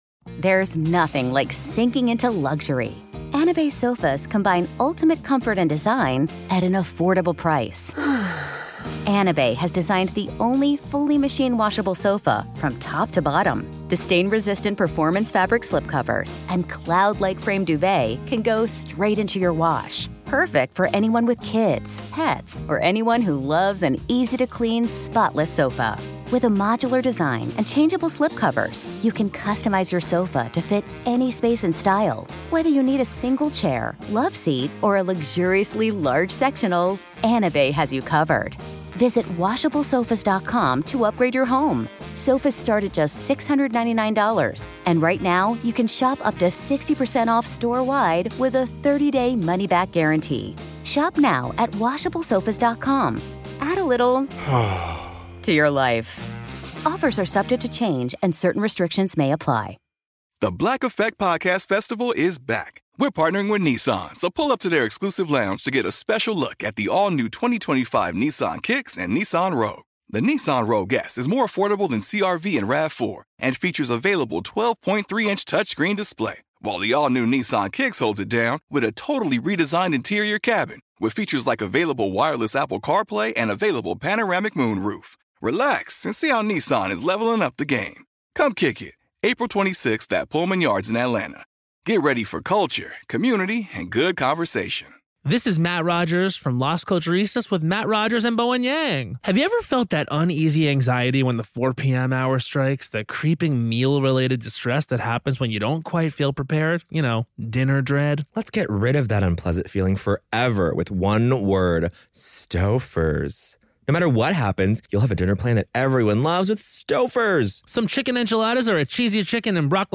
eulogy